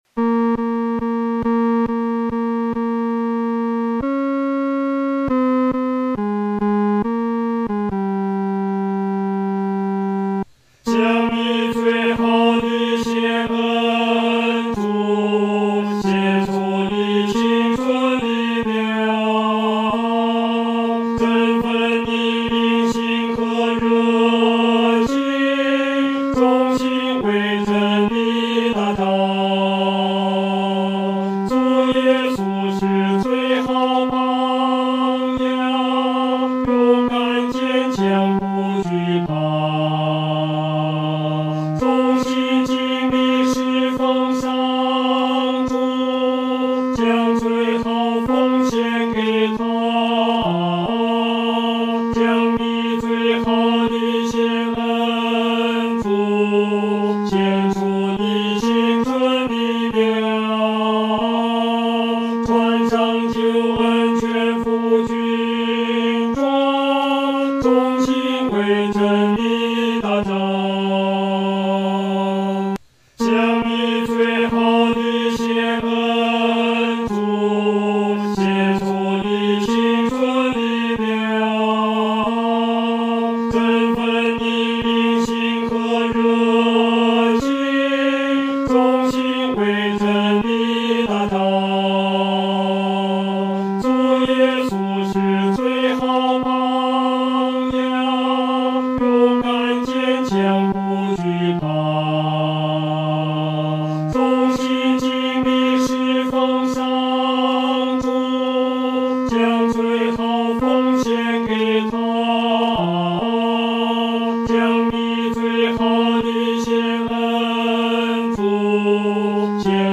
合唱
男高
本首圣诗由网上圣诗班 (南京）录制
唱时速度可以流动一些，不宜拖沓。